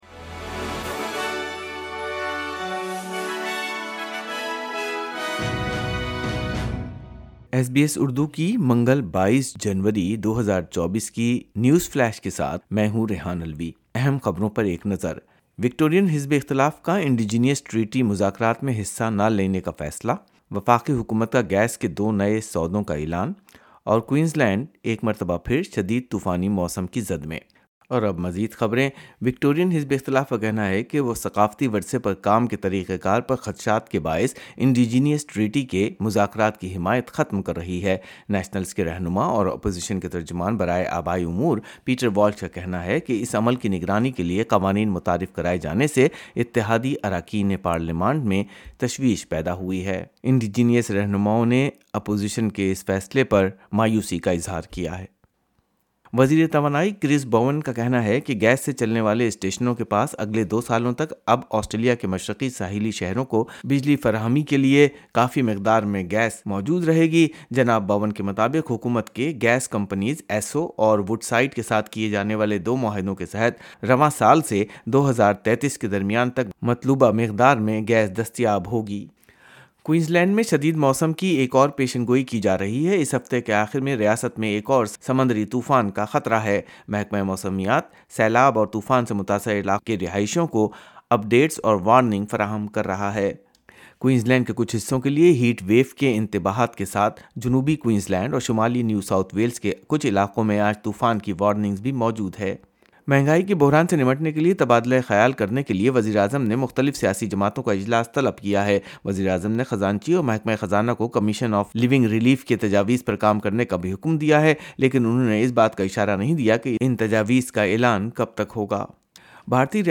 مزید تفصیل کے لئے سنئے اردو خبریں